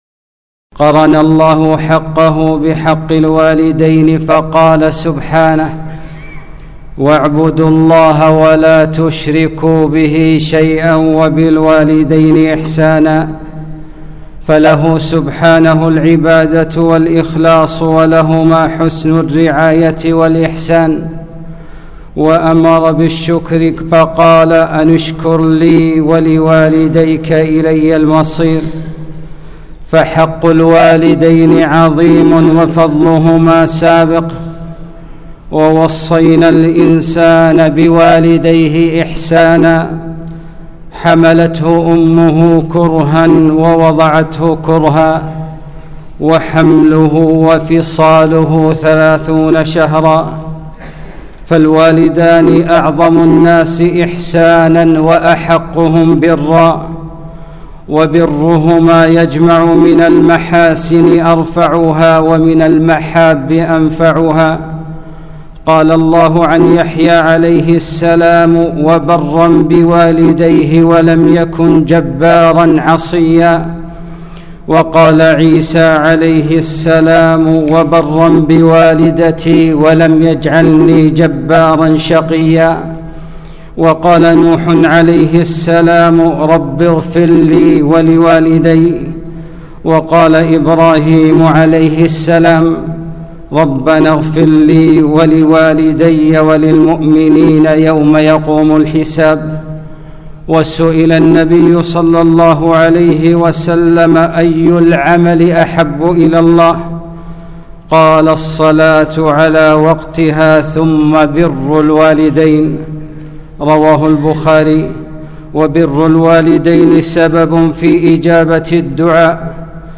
خطبة - كذلكُمُ البِر